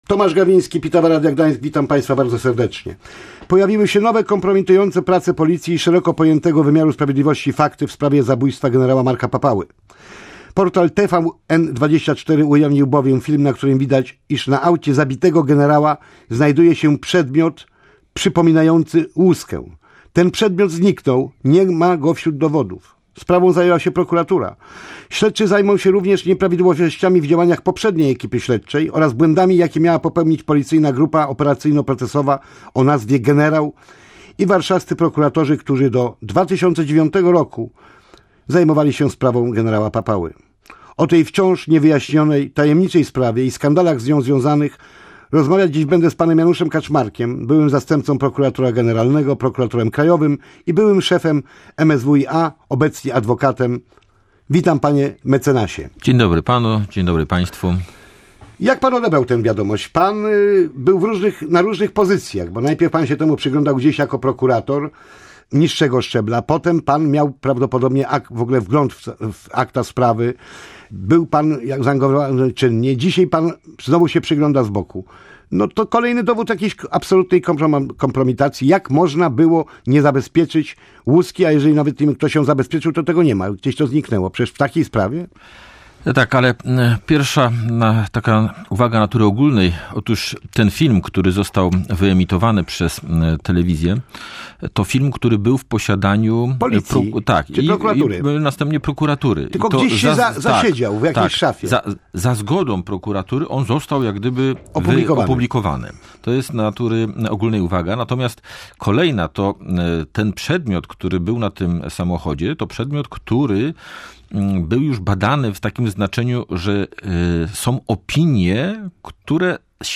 rozmawiał z Januszem Kaczmarkiem, byłym zastępcą prokuratora generalnego.